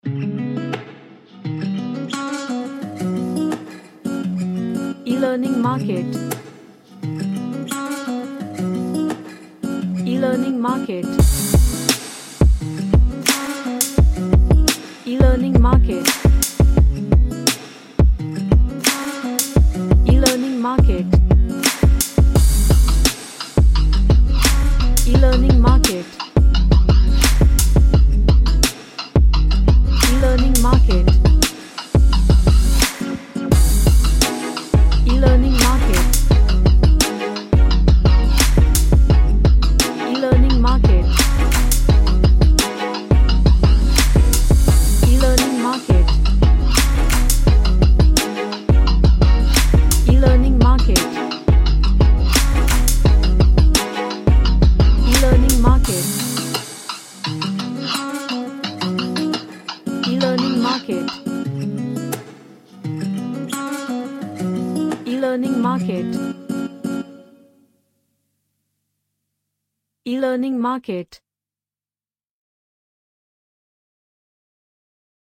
A percussive pop track with western elements
Chill Out